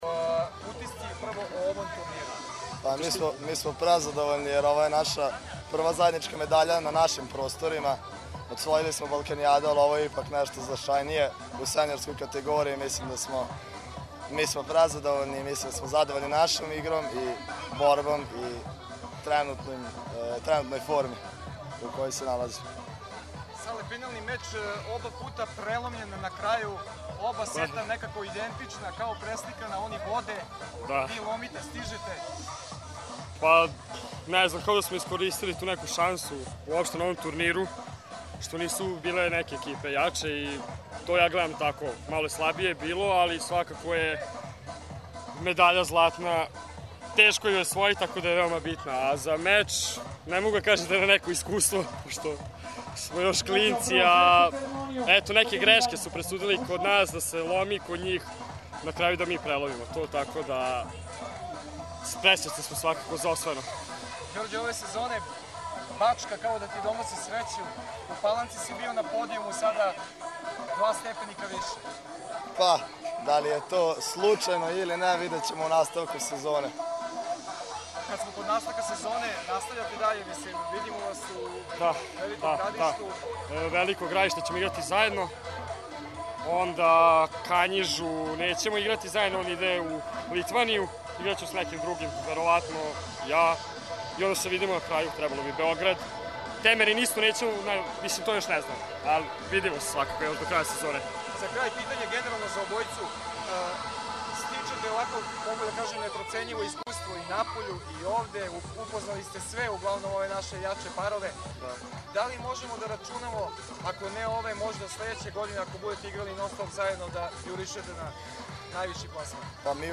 IZJAVE